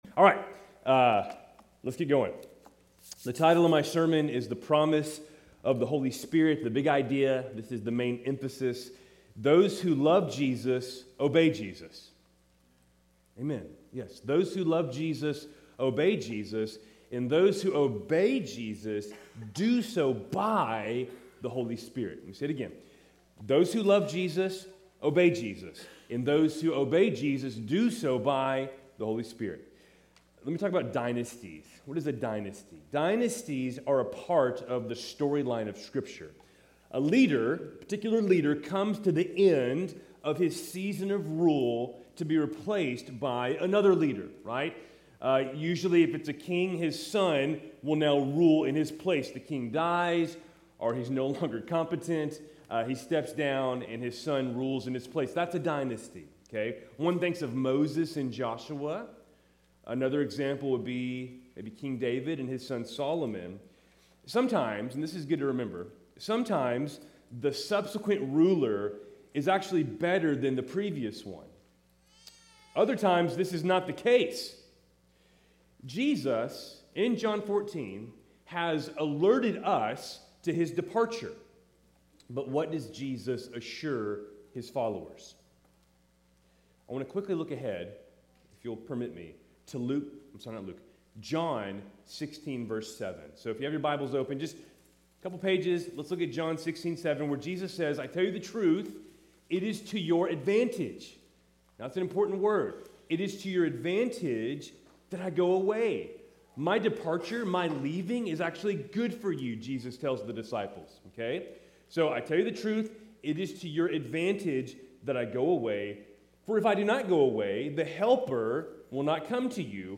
Keltys Worship Service, August 17, 2025